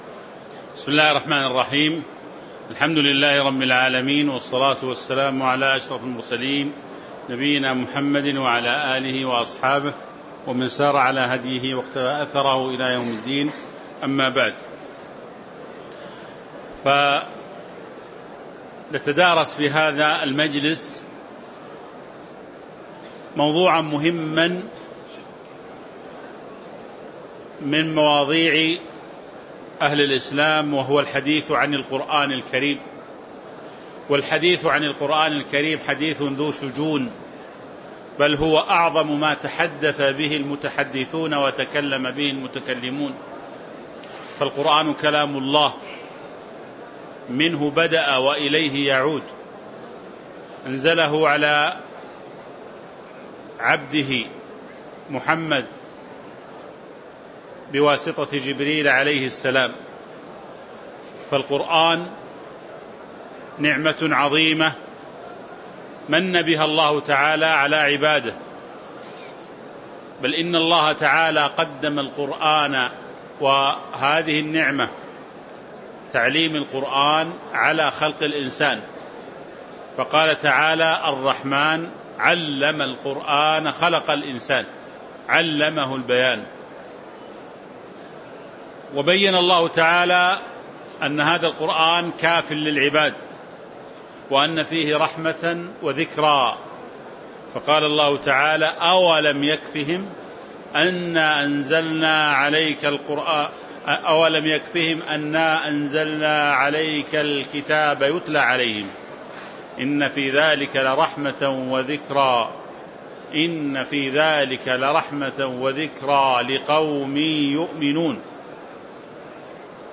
تاريخ النشر ٢ رمضان ١٤٤٦ هـ المكان: المسجد النبوي الشيخ